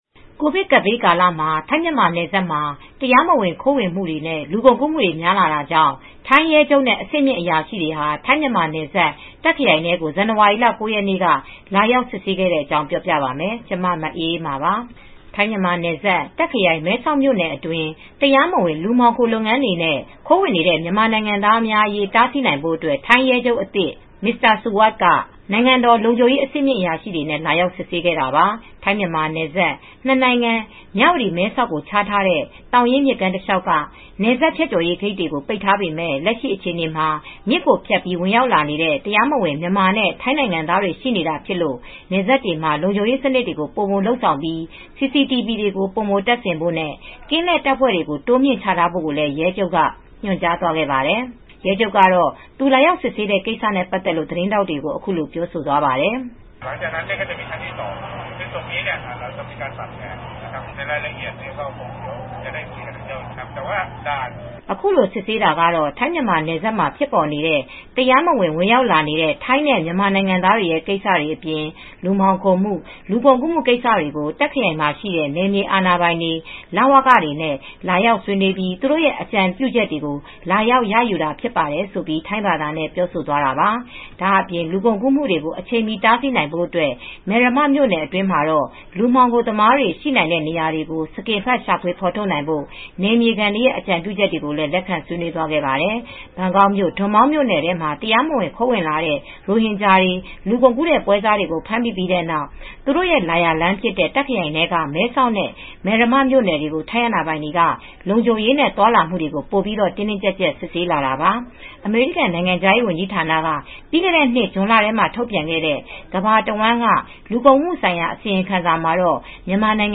ရဲချုပ်ကတော့ သူလာရောက်စစ်ဆေးတဲ့ ကိစ္စနဲ့ ပတ်သက်လို့ သတင်းထောက်တွေကို အခုလို ပြောသွားပါတယ်။